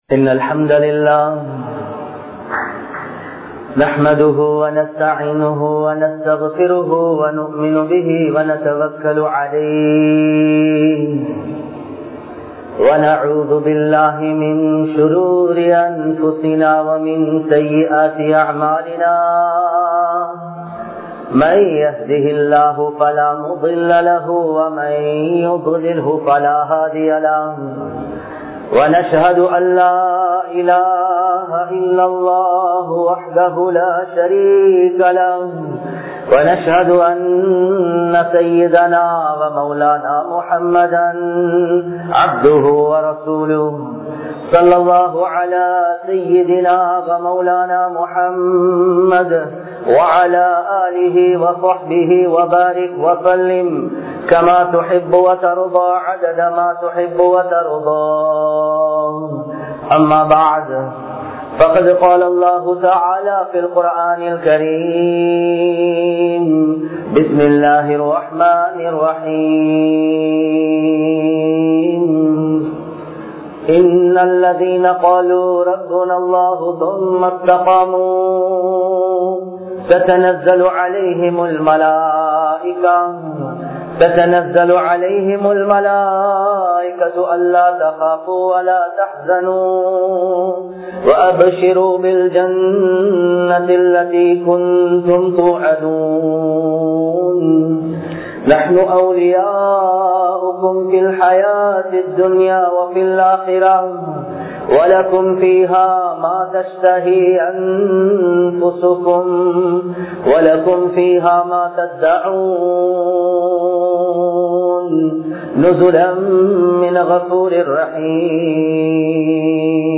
Manithanudaiya Puththikku Padaatha Arivu (மனிதனுடைய புத்திக்குப்படாத அறிவு) | Audio Bayans | All Ceylon Muslim Youth Community | Addalaichenai
Kollupitty Jumua Masjith